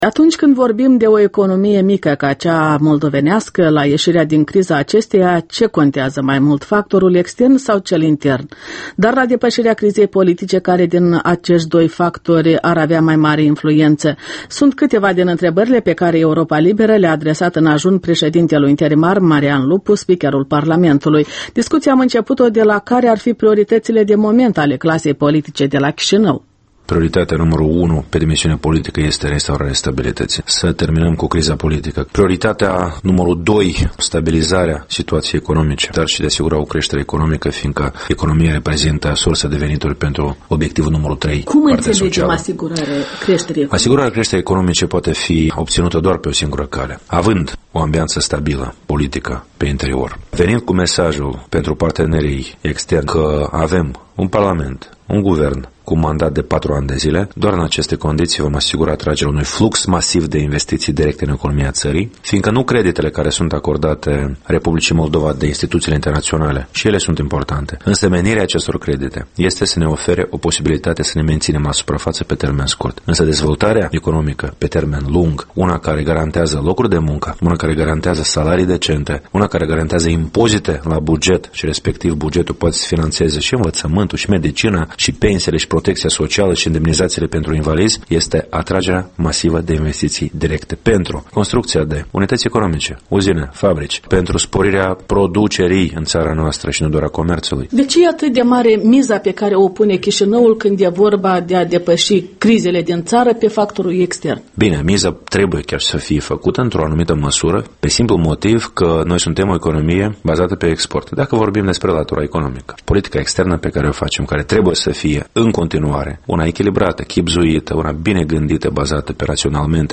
Un interviu exclusiv cu Marian Lupu, președintele Parlamentului.